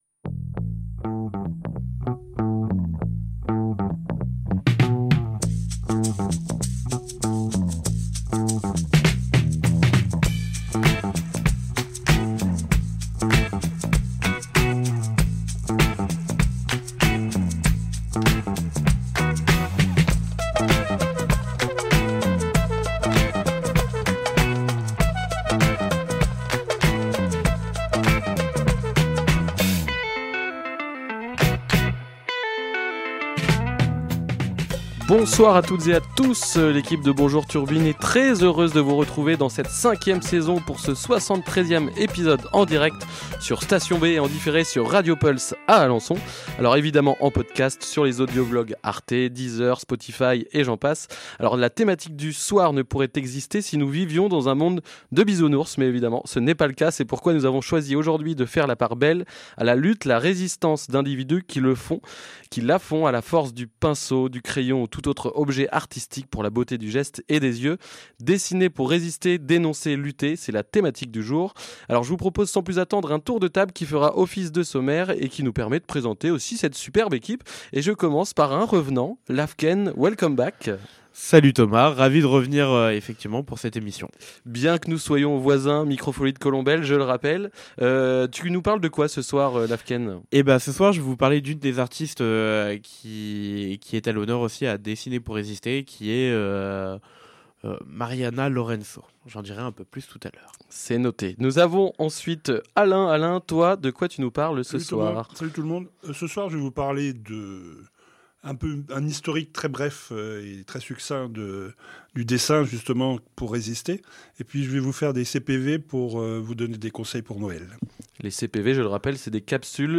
Radio Pulse 90.0FM à Alençon